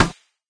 plasticmetal.ogg